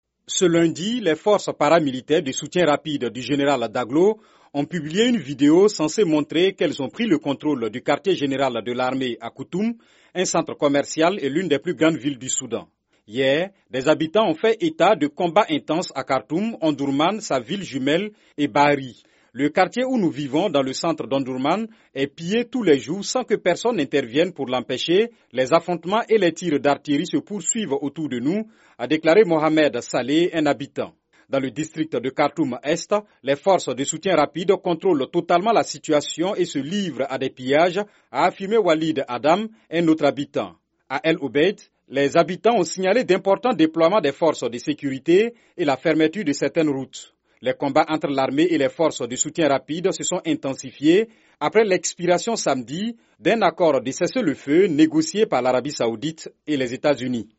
Tirs d'artillerie dans la capitale du Soudan